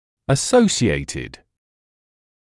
[ə’səuʃɪeɪtɪd], [ə’səusɪeɪtɪd][э’соушиэтид], [э’соусиэтид]ассоциированный, сочетанный; сопутствующий; сопряжённый